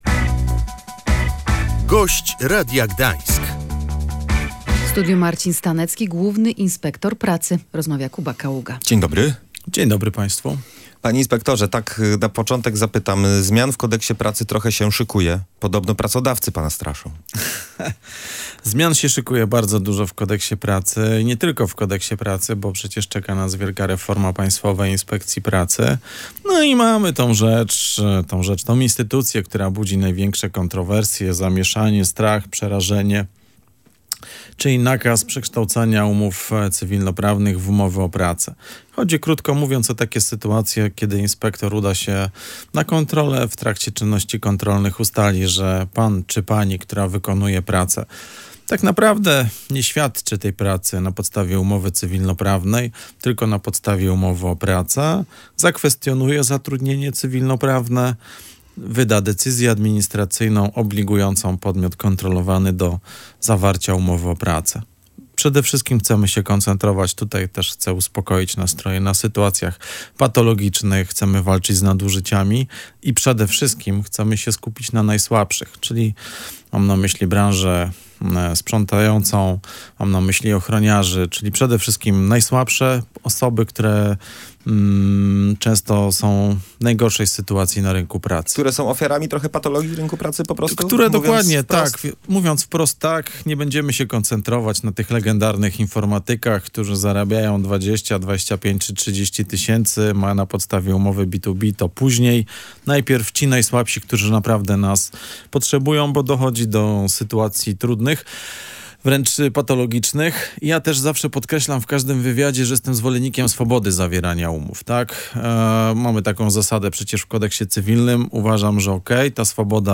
Będziemy skupiać się na sytuacjach patologicznych – zapowiedział na naszej antenie Marcin Stanecki, Główny Inspektor Pracy.
Jak zapowiedział gość Radia Gdańsk, w przyszłym roku inspekcja chciałaby przeprowadzić około 200 kontroli.